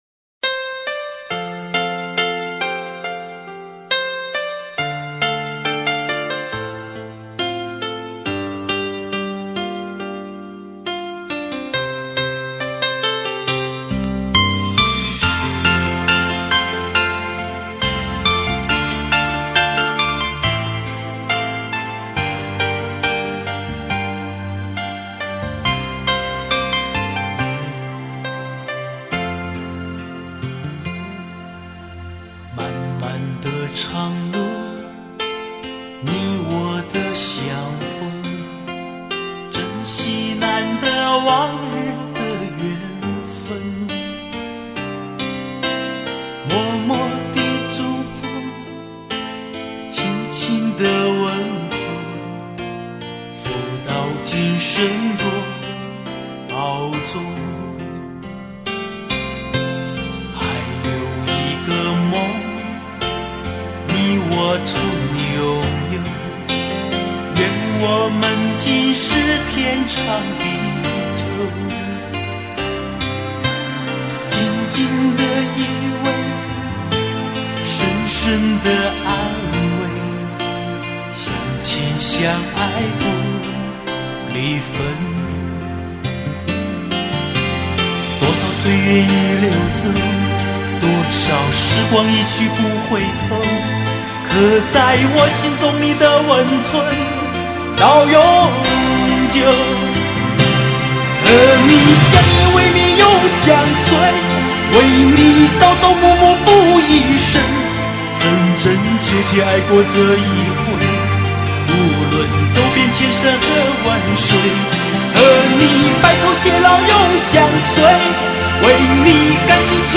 无损音质原人原唱，经典！值得聆听永久珍藏